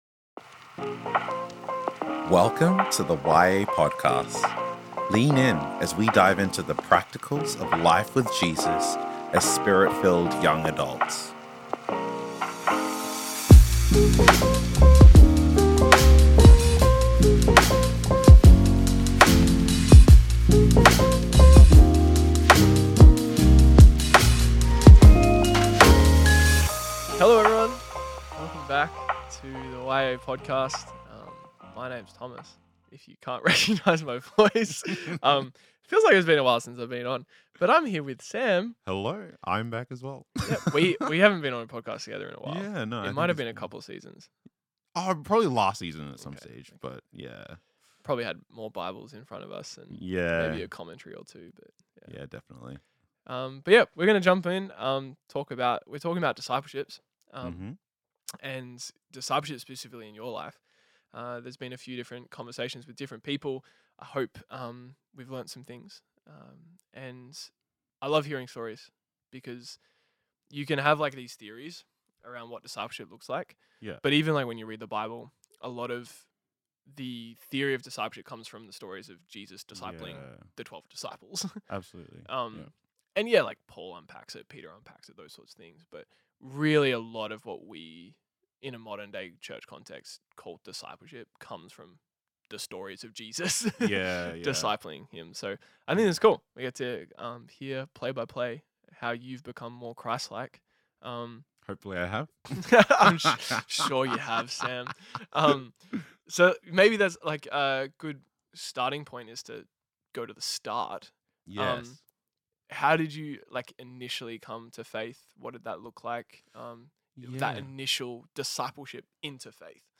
Discipleship Conversations: Intentionality